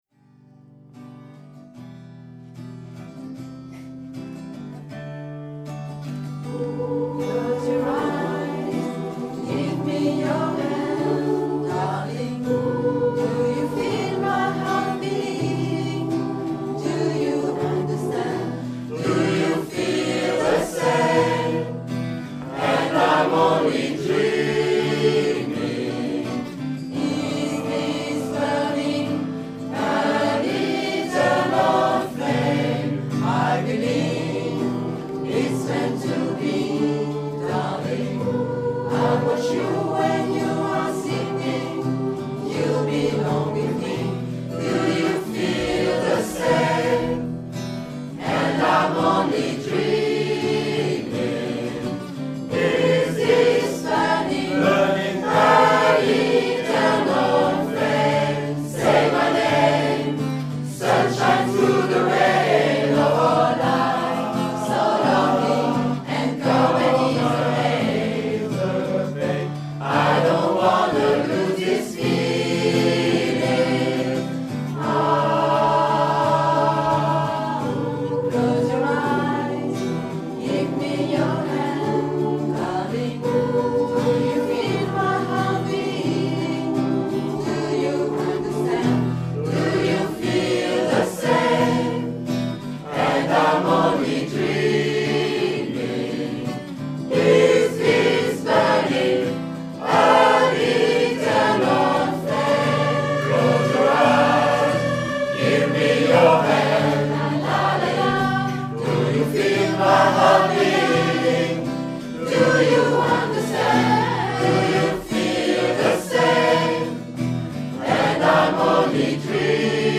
HISTORIQUE DES CHANTS HARMONISES & INTERPRETES DEPUIS 2005